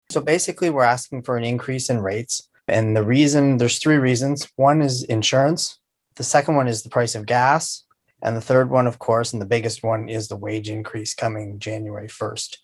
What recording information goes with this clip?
spoke before town council at this weeks meeting